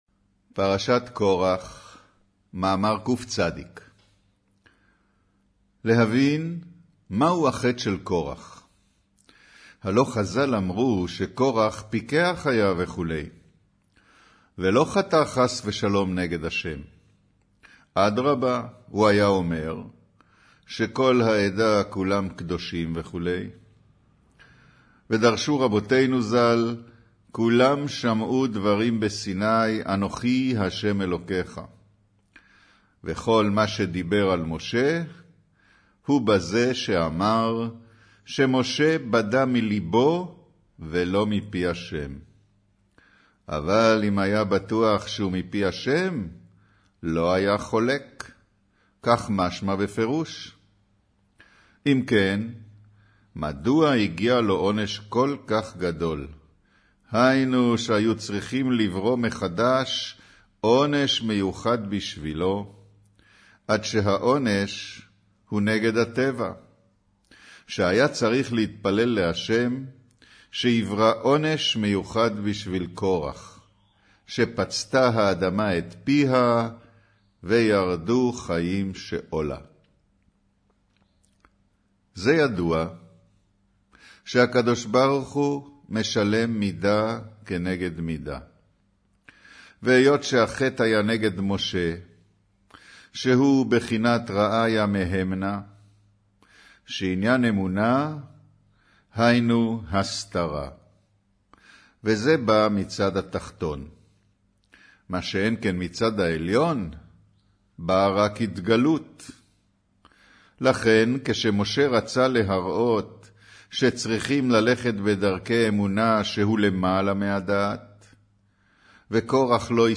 קריינות פרשת קרח